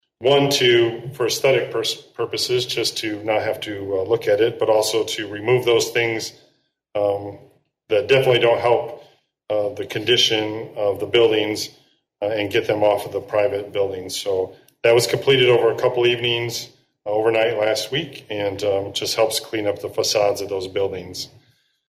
Coldwater City Manager Keith Baker told the City Council on Monday night the work was done for two reasons.